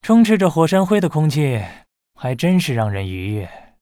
文件 文件历史 文件用途 全域文件用途 Bhan_tk_03.ogg （Ogg Vorbis声音文件，长度3.8秒，90 kbps，文件大小：42 KB） 源地址:地下城与勇士游戏语音 文件历史 点击某个日期/时间查看对应时刻的文件。